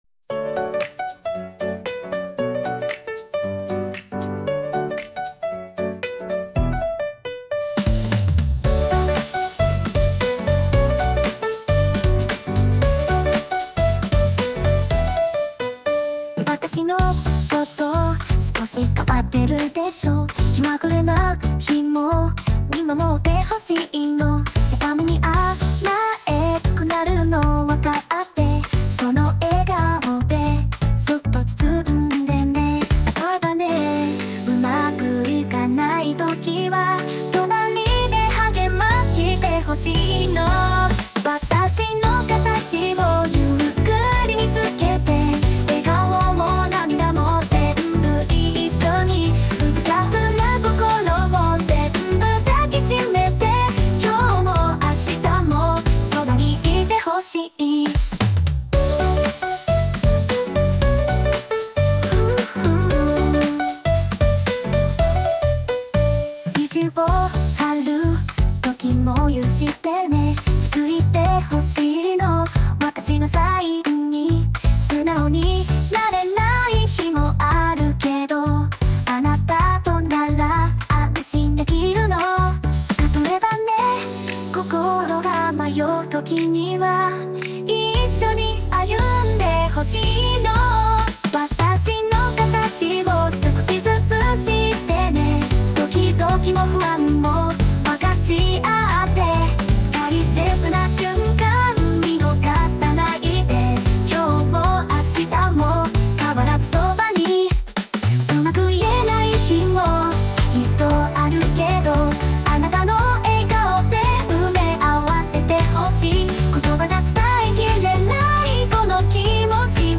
著作権フリーオリジナルBGMです。
女性ボーカル（邦楽・日本語）曲です。
日本語で可愛らしいキュートな曲を作りたくて制作しました✨
イメージ通りカワイイ感じに仕上がったのでぜひお聴きください。